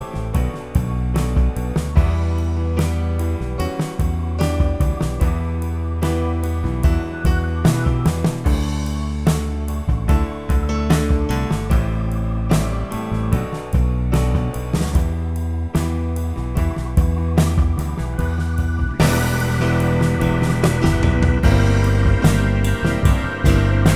Minus Acoustic Pop (1990s) 3:46 Buy £1.50